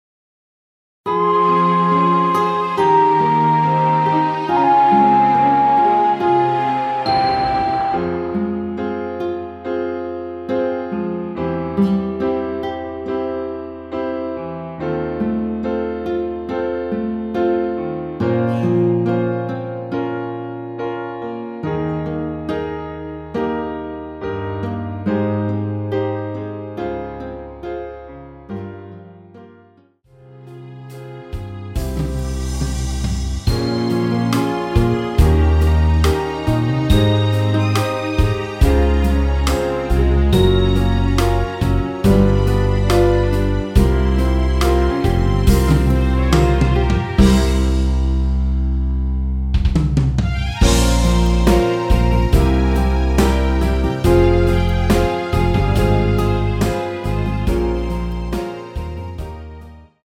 Fm
앞부분30초, 뒷부분30초씩 편집해서 올려 드리고 있습니다.